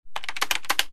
Keyboard2.wav